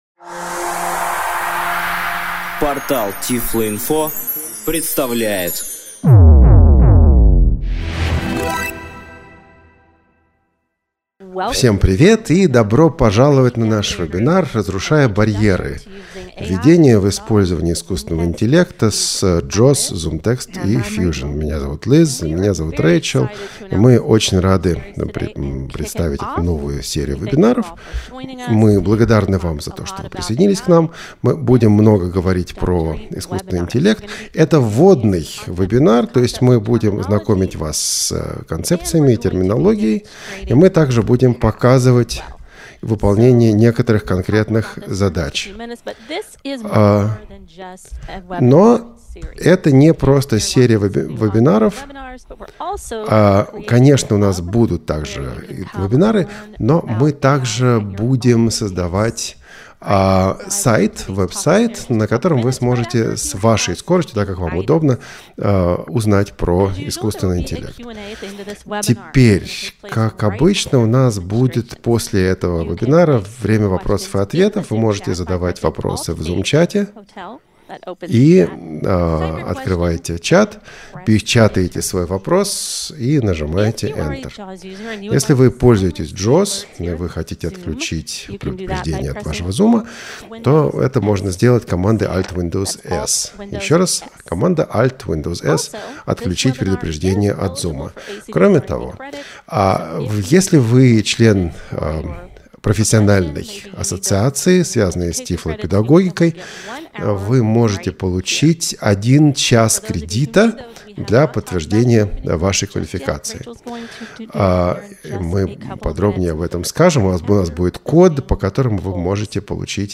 Вебинар: Знакомство с ChatGPT: Диалог. Креативность. Ясность